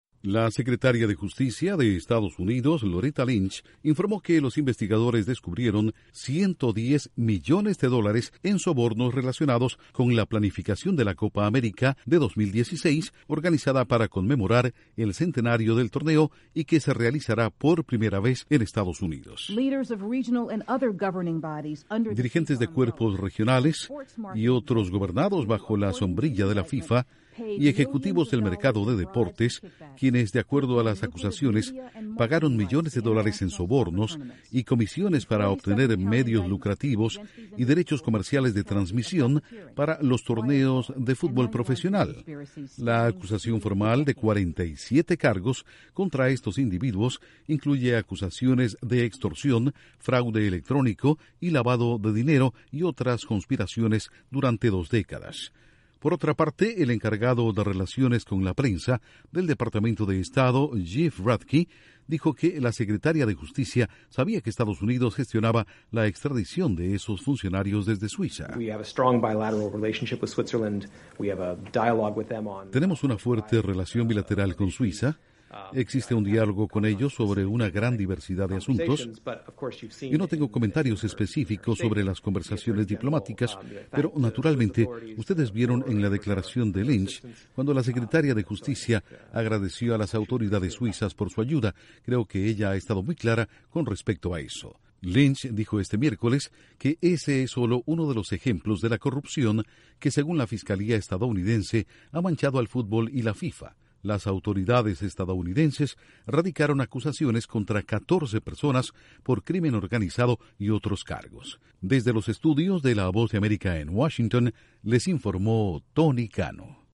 Secretaria de justicia de Estados Unidos enumera algunos cargos contra los detenidos de FIFA, al tiempo que se le pregunta al Departamento de Estado sobre el proceso de extradición a Estados Unidos. Informa desde los estudios de la Voz de América en Washington